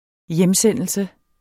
Udtale [ -ˌsεnˀəlsə ]